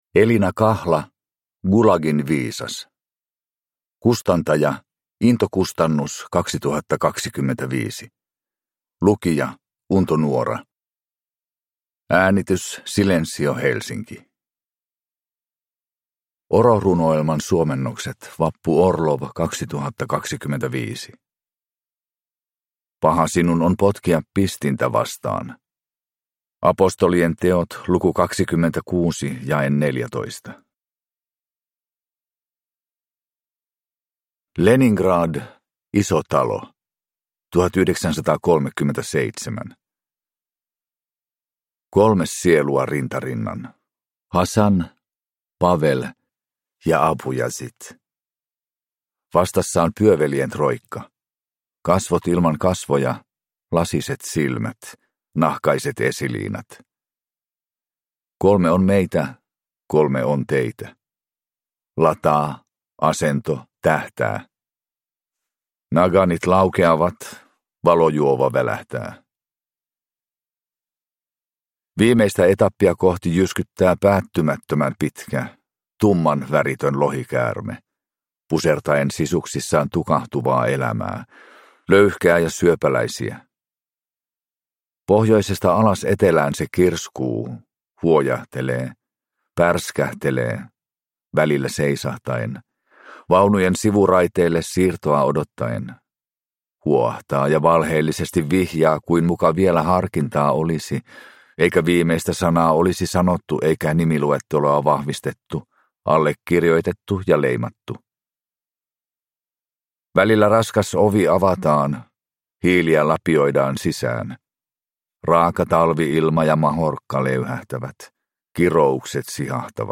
Gulagin viisas (ljudbok) av Elina Kahla